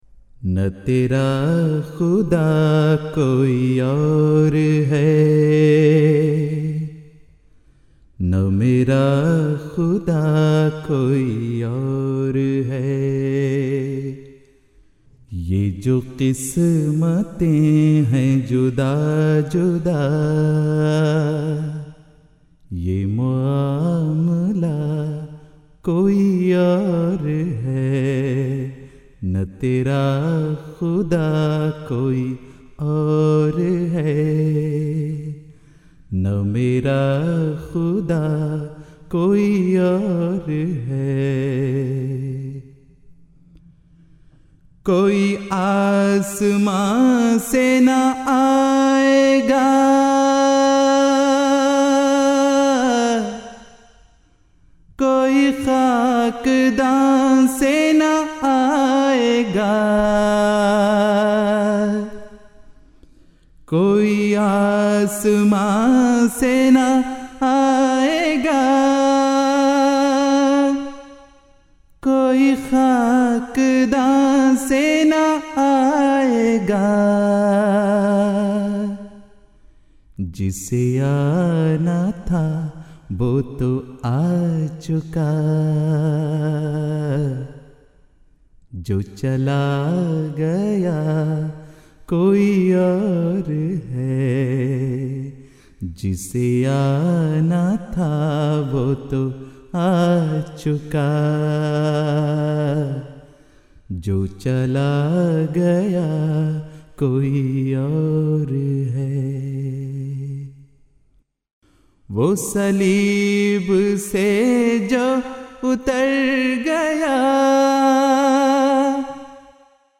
Urdu Poems
MTA 2006 (Jalsa UK)